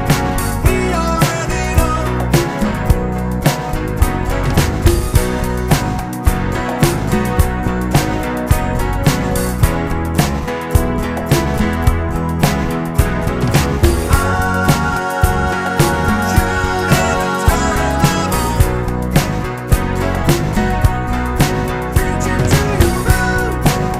One Semitone Down Pop (1970s) 4:17 Buy £1.50